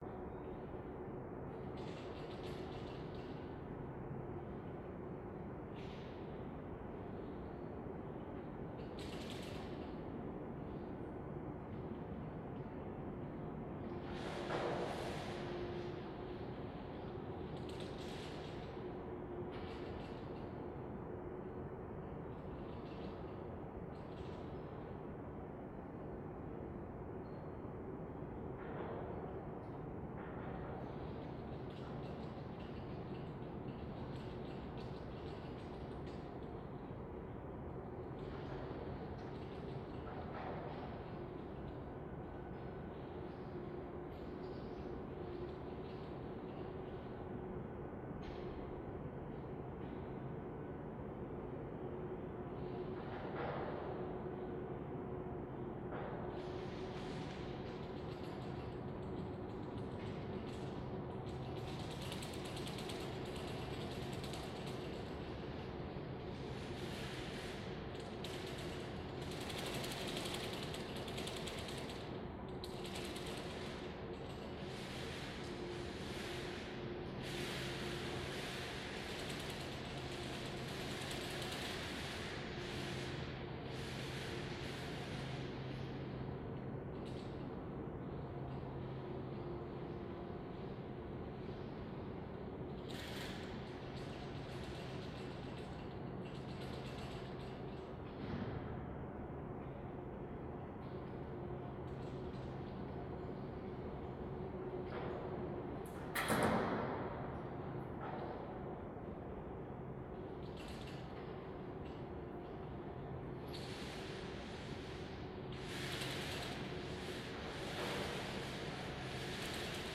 Abandoned Warehouse.ogg